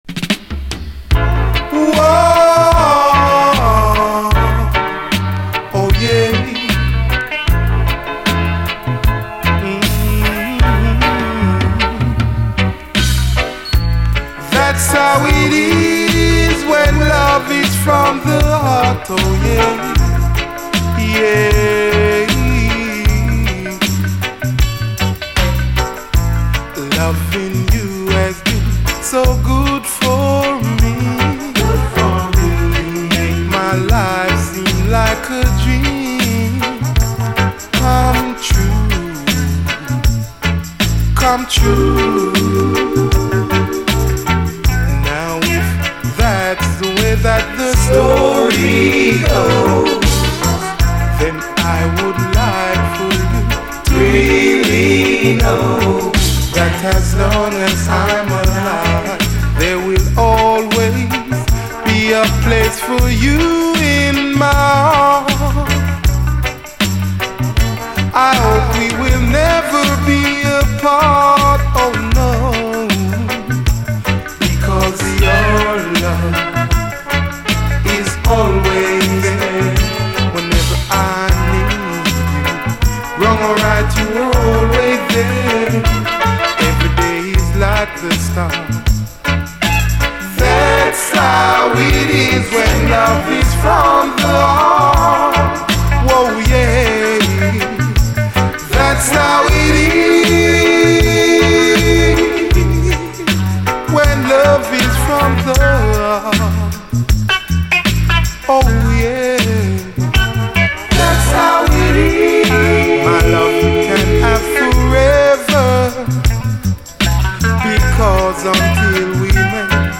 REGGAE
コーラス・ワークがめちゃめちゃイイ。